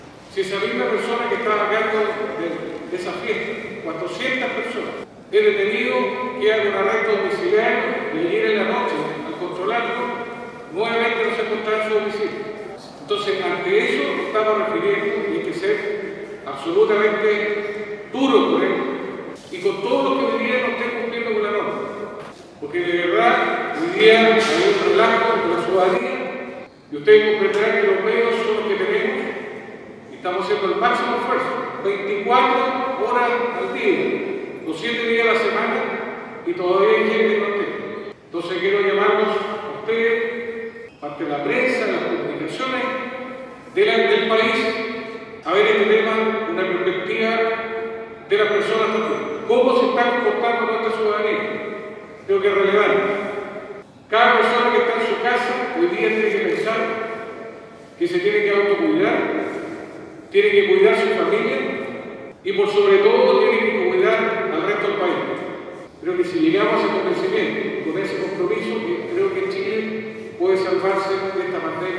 La declaración la dio a conocer hoy durante el punto de prensa convocado por él y el Ministro de Defensa, Alberto Espina, para crear “enlaces directos” con los alcaldes de la Asociación de Municipalidades de Chile -AMUCH-, que permitan fiscalizar de forma más eficiente el quebrantamiento de las medidas impuestas por la autoridad sanitaria, ante los últimos hechos acontecidos este fin de semana como la fiesta masiva en Maipú, durante el Estado de Excepción y en pleno Toque de Queda.